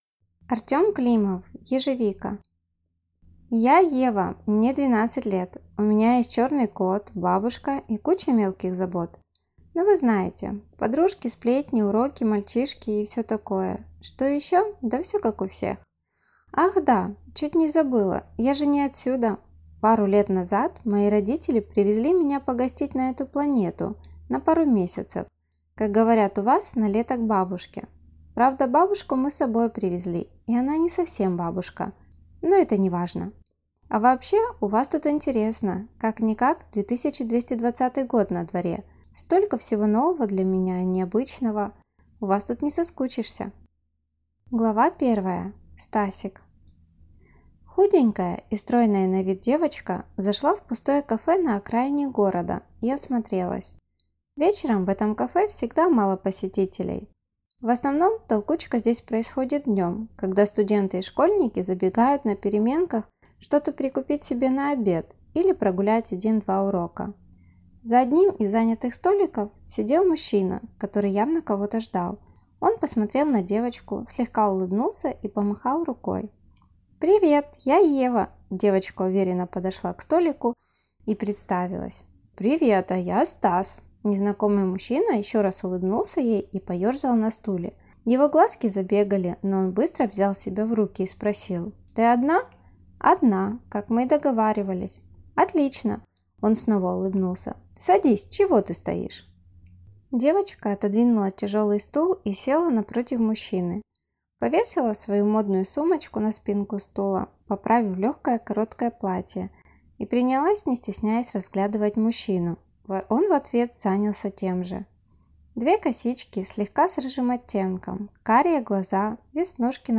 Аудиокнига Ежевика | Библиотека аудиокниг